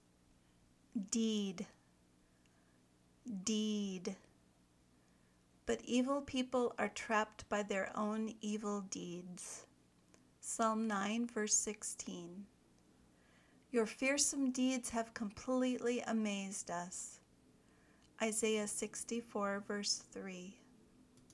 did  (noun)